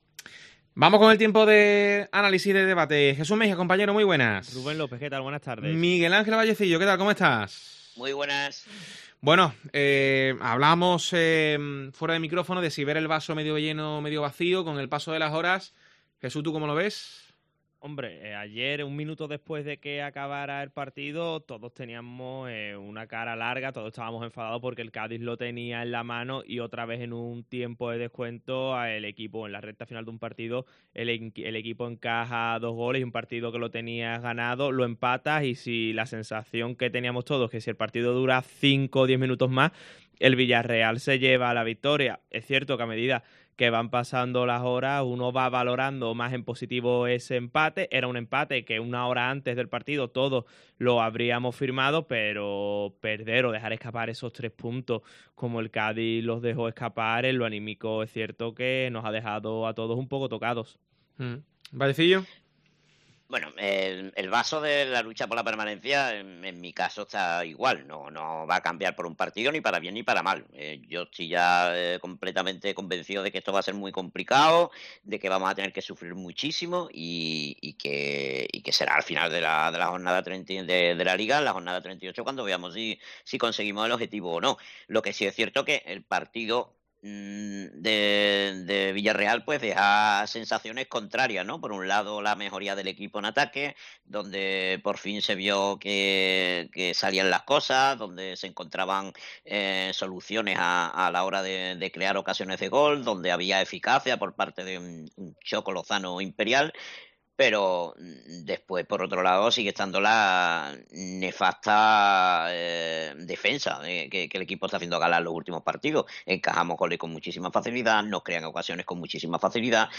El debate del Cádiz CF